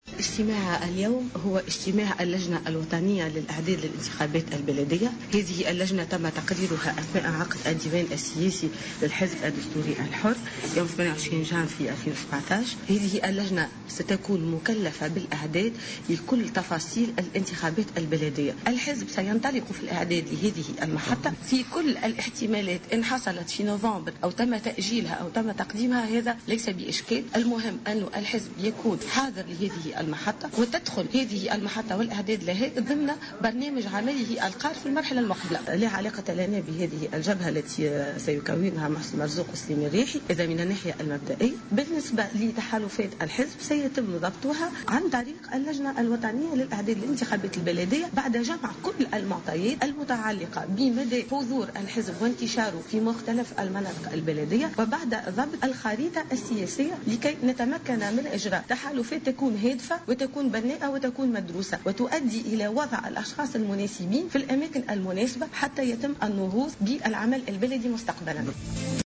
وأضافت في تصريح لمراسل "الجوهرة أف أم" على هامش انعقاد اجتماع للحزب بالمنستير اليوم " لا علاقة لنا بالجبهة التي سيكوّنها محسن مرزوق و سليم الرياحي...وبالنسبة لتحالفات الحزب سيتم ضبطها عن طريق اللجنة الوطنية المكلفة بالإعداد للانتخابات البلدية."